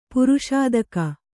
♪ puruṣādaka